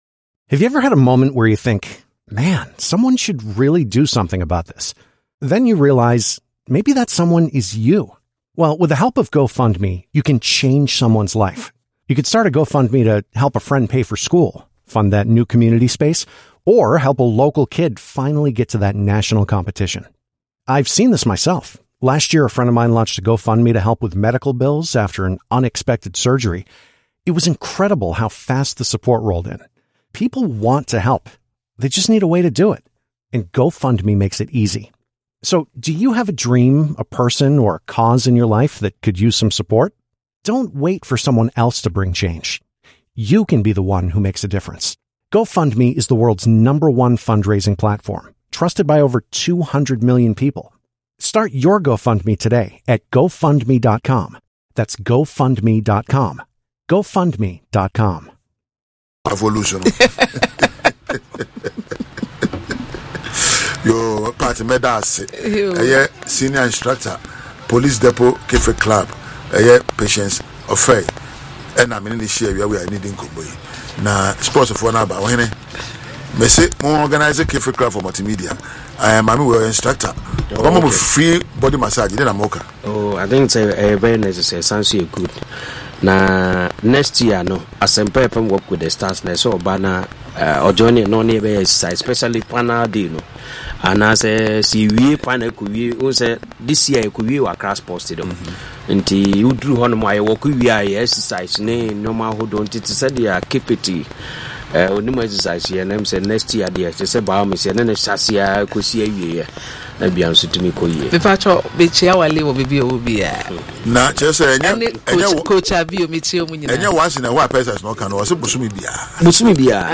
Soccer commentary of local leagues and discussion of major sports happenings over the weekend including pre-match discussions